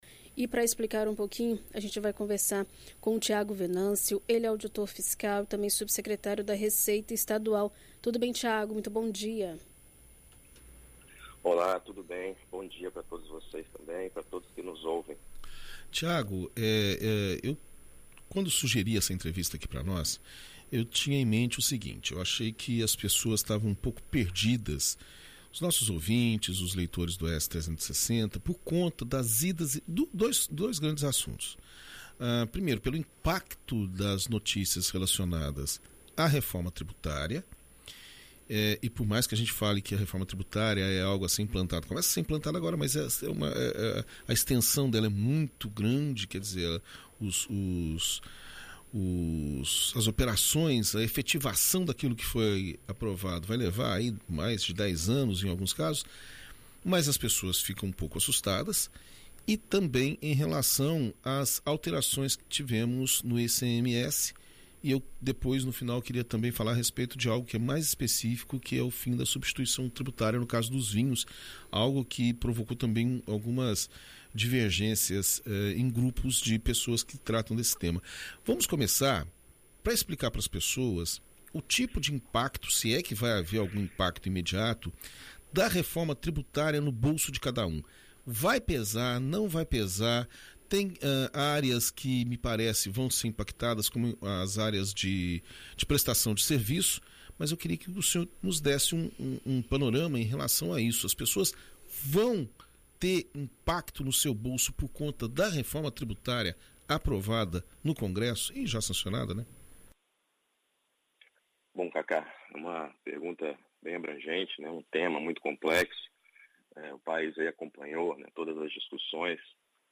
Em entrevista à BandNews FM Espírito Santo nesta quinta-feira (04), o auditor fiscal e subsecretário da Receita Estadual, Thiago Venâncio, fala sobre como é aplicado o Imposto sobre Circulação de Mercadorias e Serviços (ICMS) em produtos do estado e também fala sobre como fica a substituição tributária dos vinhos.